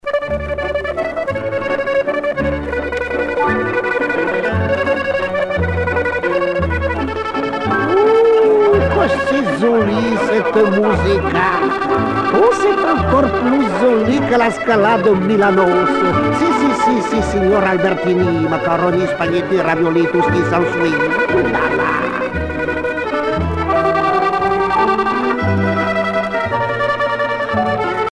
valse musette
Pièce musicale éditée